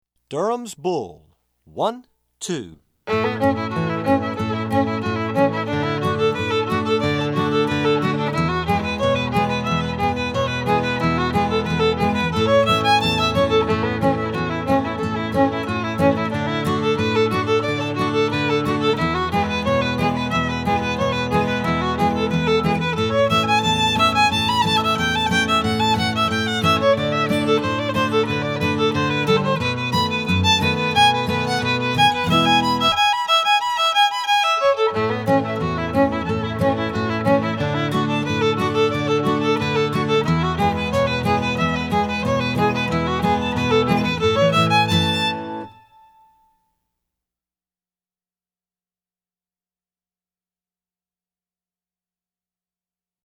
DIGITAL SHEET MUSIC - FIDDLE SOLO